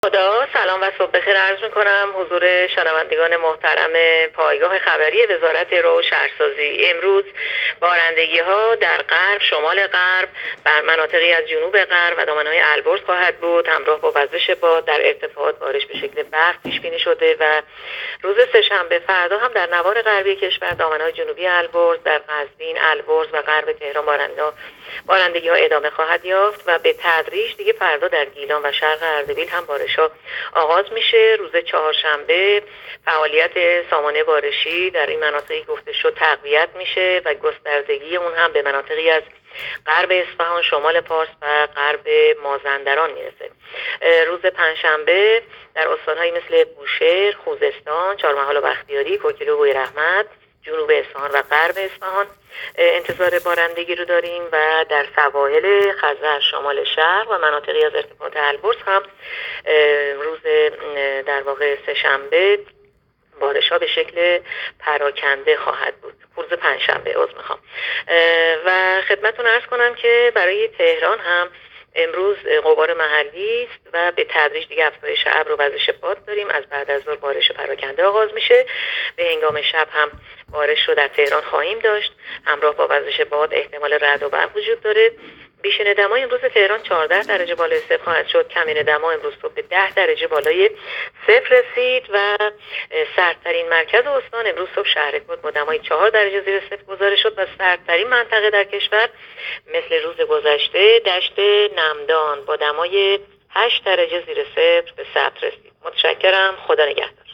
گزارش رادیو اینترنتی پایگاه‌ خبری از آخرین وضعیت آب‌وهوای ۱۷ آذر؛